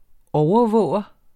Udtale [ ˈɒwʌˌvɔˀwʌ ]